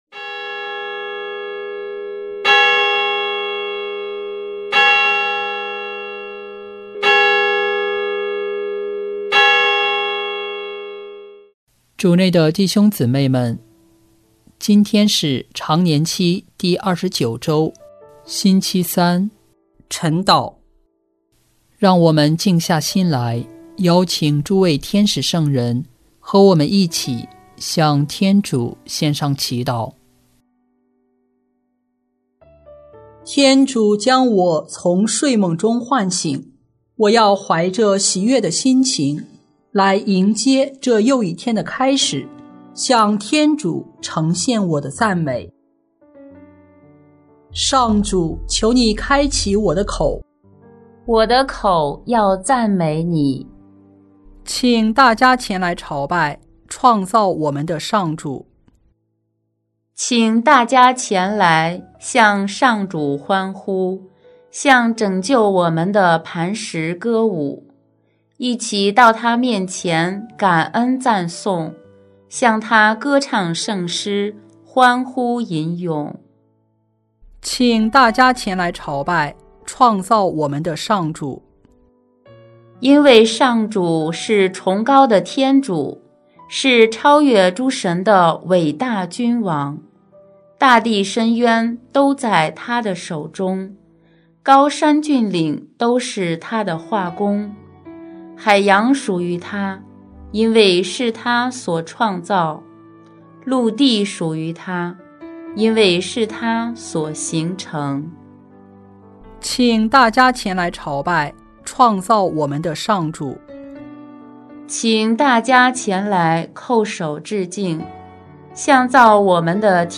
【每日礼赞】|10月22日常年期第二十九周星期三晨祷